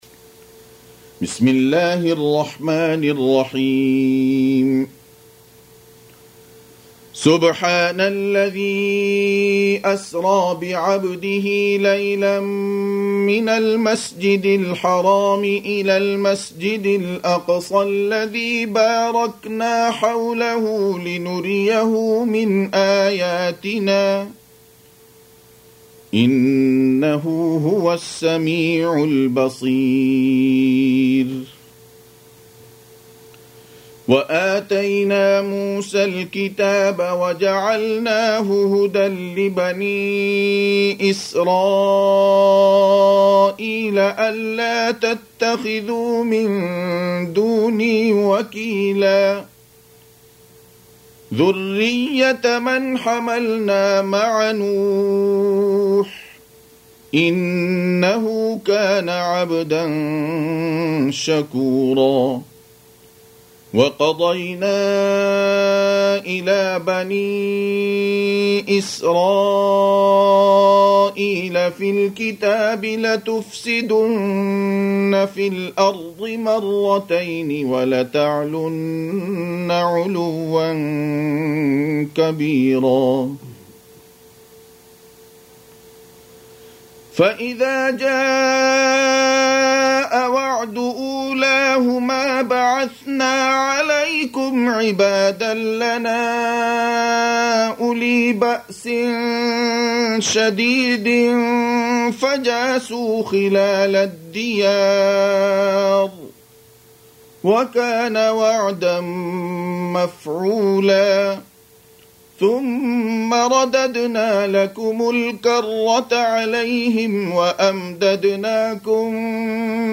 Surah Repeating تكرار السورة Download Surah حمّل السورة Reciting Murattalah Audio for 17. Surah Al-Isr�' سورة الإسراء N.B *Surah Includes Al-Basmalah Reciters Sequents تتابع التلاوات Reciters Repeats تكرار التلاوات